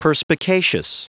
Prononciation du mot perspicacious en anglais (fichier audio)
Prononciation du mot : perspicacious